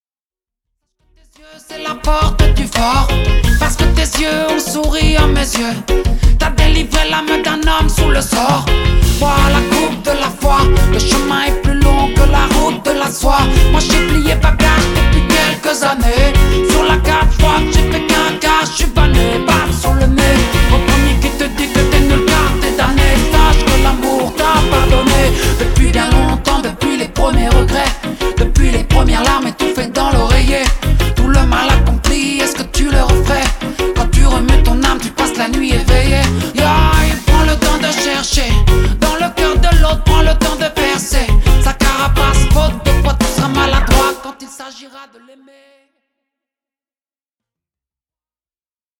reggae
Enregistré dans un grand studio de Bruxelles